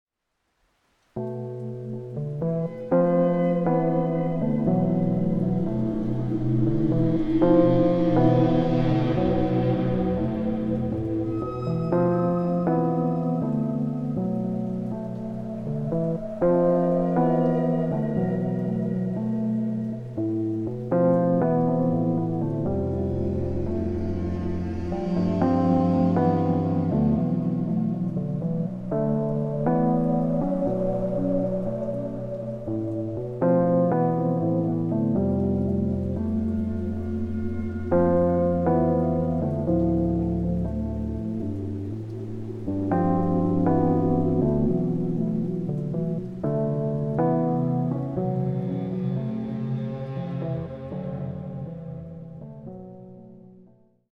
Саунд-дизайн на тему "дождливой дороги"...
Sound design for "rain and road" spirit... VST Rhodes, Guitar Rig, FX-design, SoundLogic.